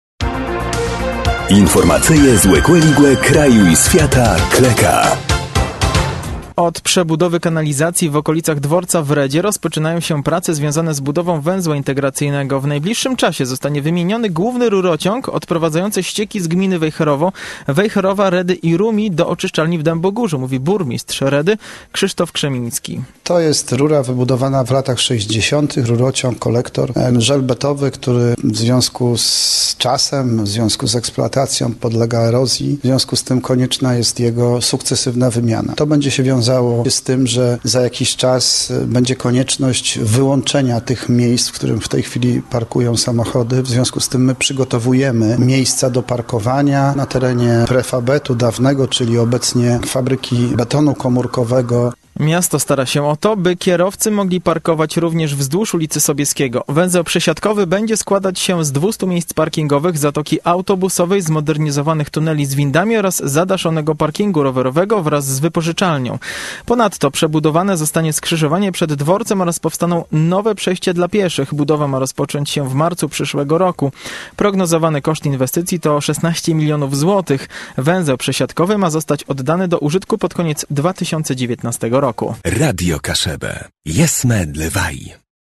W najbliższym czasie zostanie wymieniony główny rurociąg, odprowadzający ścieki z gminy Wejherowo, Wejherowa, Redy i Rumi do oczyszczalni w Dębogórzu, mówi burmistrz Redy, Krzysztof Krzemiński.